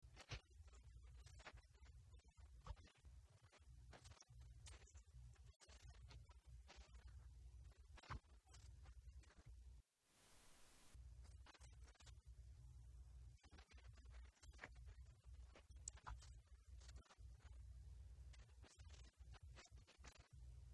When discussion resumed, Deputy Mayor Lori Hoddinott conveyed the decisions.